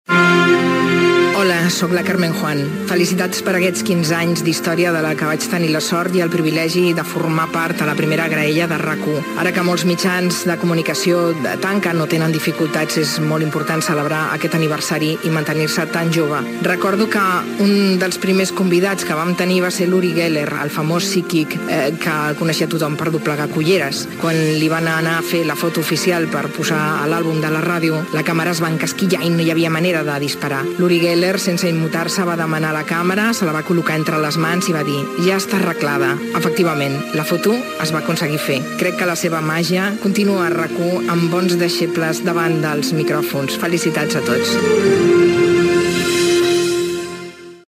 Felicitacions pels 15 anys de RAC 1.